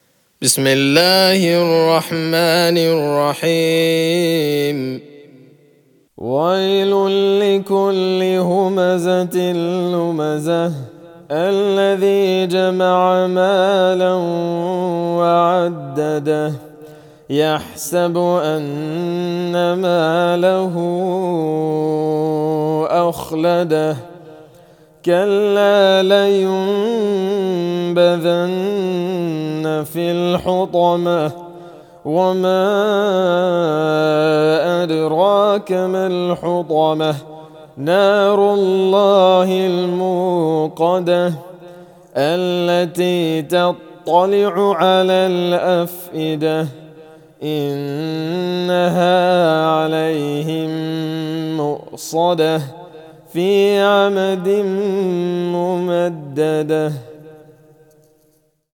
Captions English Recitation of Surah Al-Humazah in murattal.
Chapter_104,_Al-Humazah_(Murattal)_-_Recitation_of_the_Holy_Qur'an.mp3